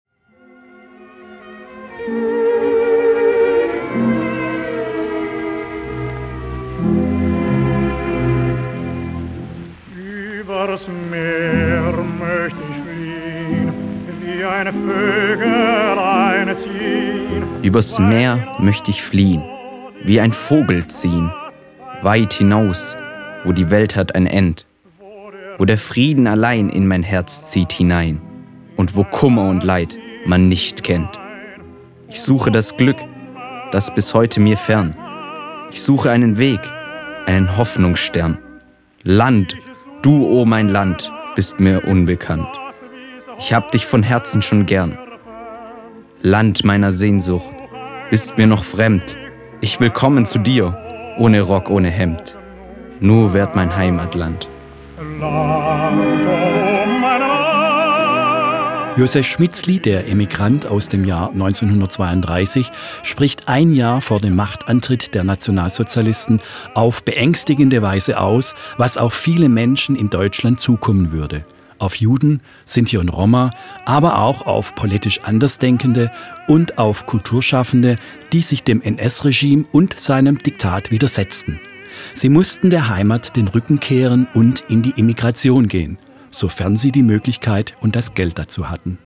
Und wir spielen ihre Songs: